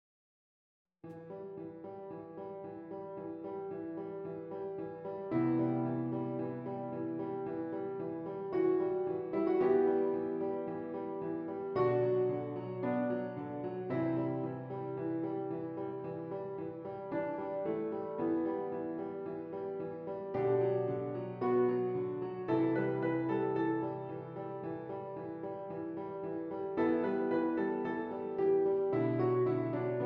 E Minor
Andante